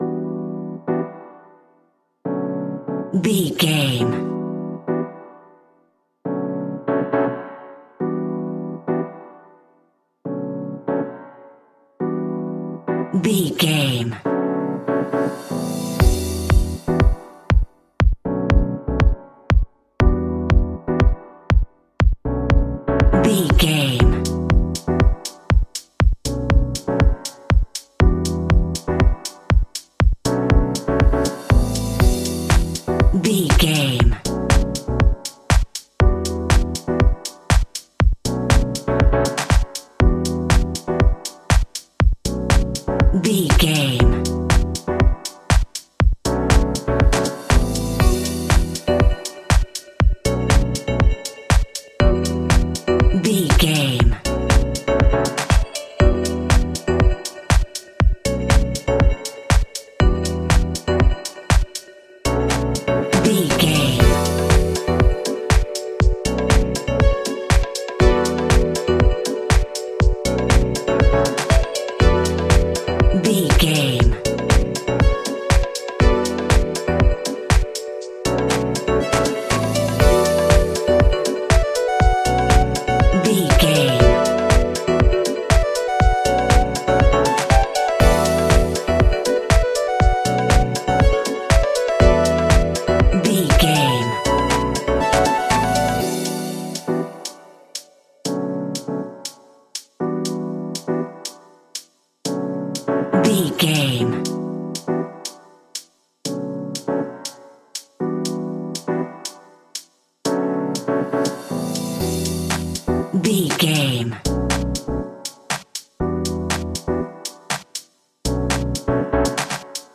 Dorian
groovy
uplifting
driving
energetic
drum machine
synthesiser
funky house
disco
upbeat
funky guitar
clavinet
synth bass
horns